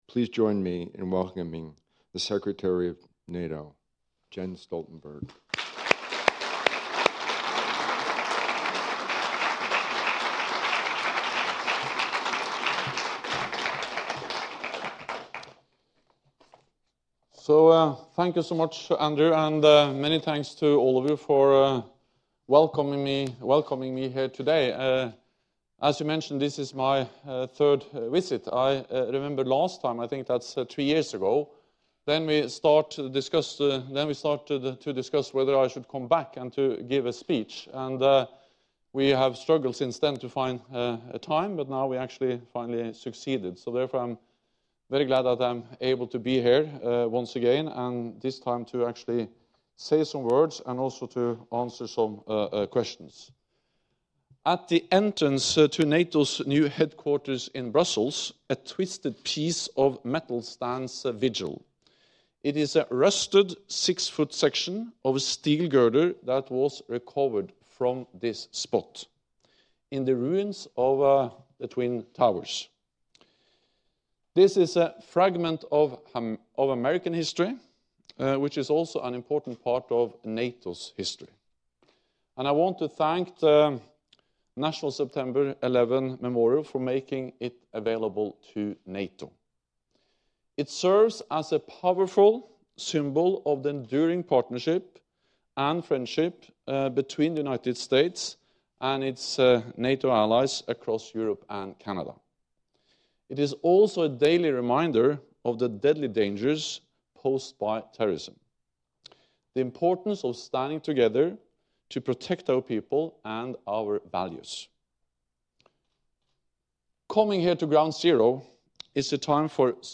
Remarks by NATO Secretary General Jens Stoltenberg at the National September 11 Memorial & Museum, New York
(As delivered)
(followed by Q&A session)